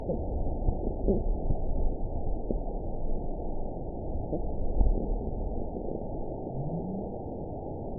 event 917096 date 03/19/23 time 23:25:51 GMT (2 years, 1 month ago) score 9.66 location TSS-AB04 detected by nrw target species NRW annotations +NRW Spectrogram: Frequency (kHz) vs. Time (s) audio not available .wav